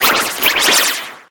Cri de Rongrigou dans Pokémon HOME.